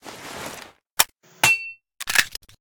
barrelswap.ogg